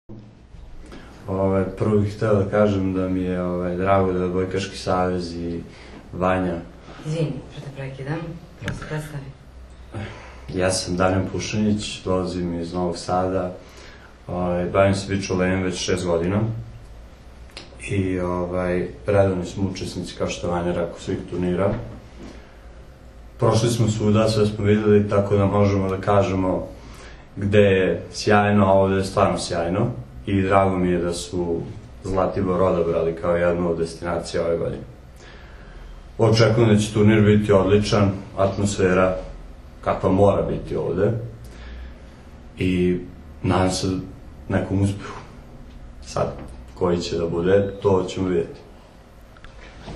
Na Zlatiboru startovala “Kids liga” i “Vip Beach Masters škole odbojke” – održana konferencija za novinare
IZJAVA